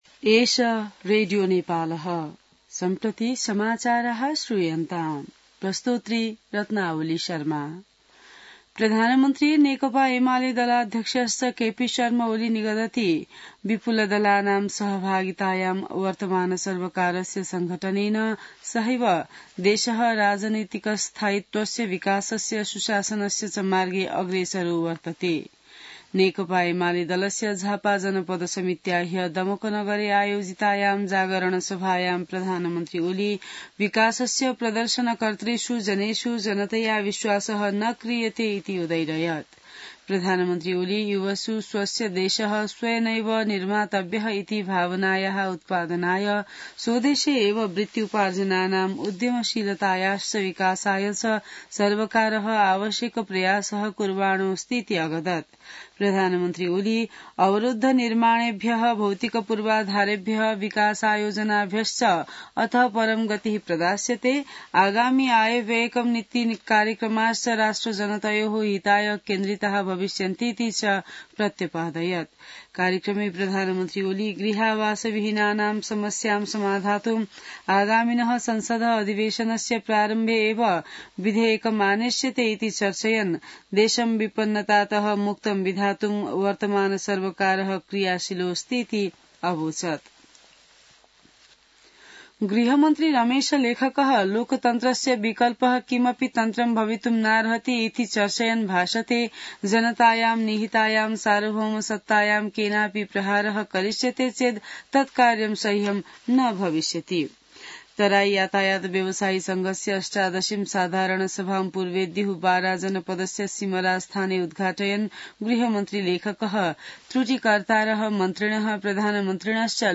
संस्कृत समाचार : ३० चैत , २०८१